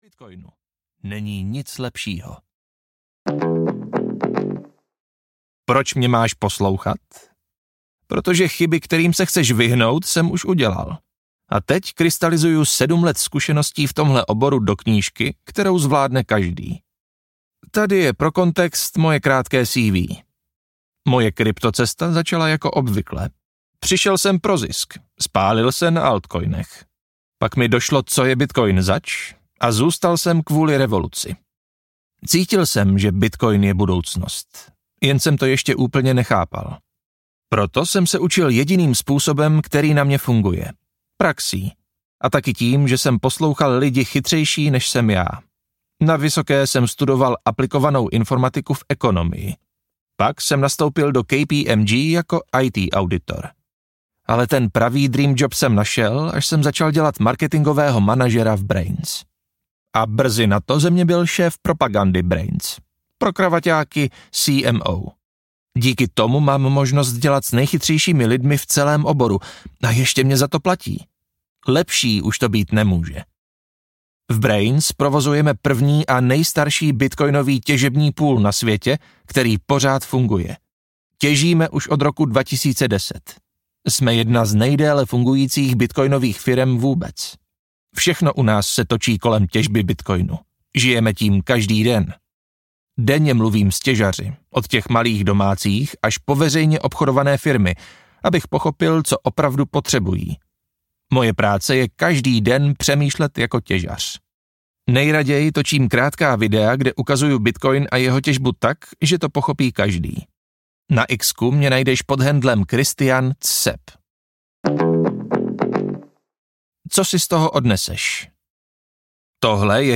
Ukázka z knihy
ultimatni-pruvodce-tezbou-bitcoinu-audiokniha